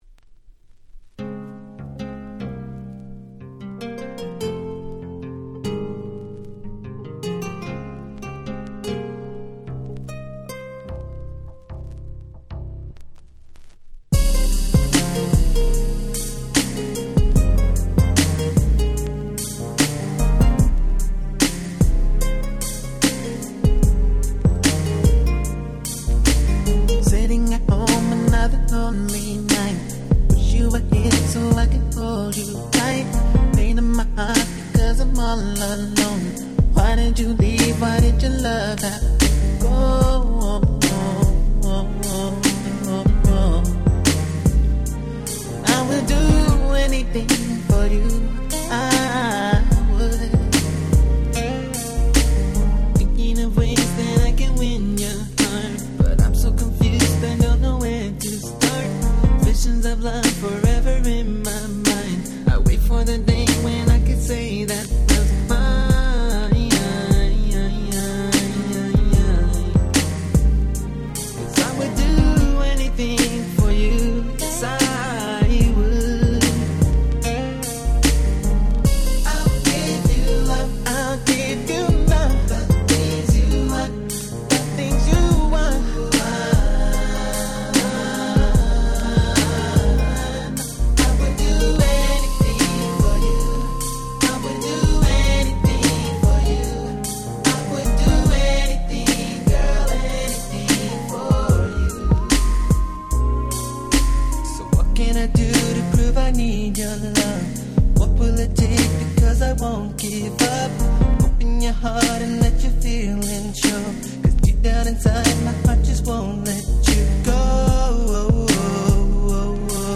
95' Nice R&B / Slow Jam !!